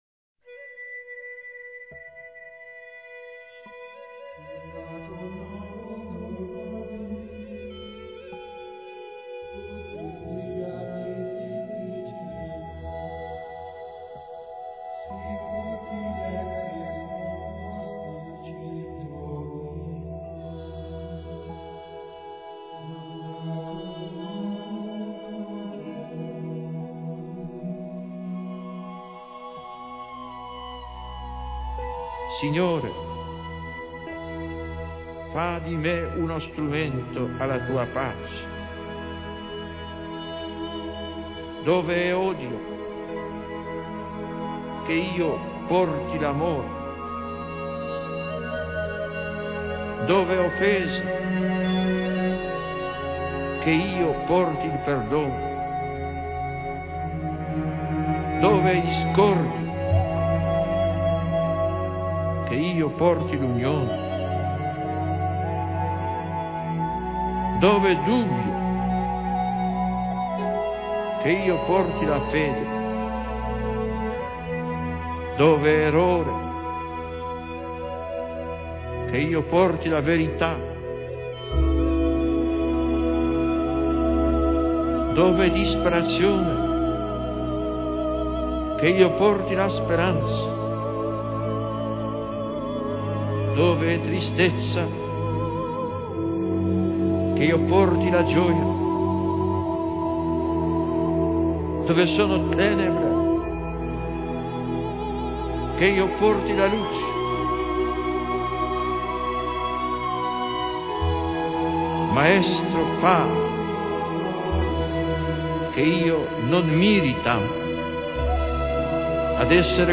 (Rezada por Juan Pablo II)
Audio de JPII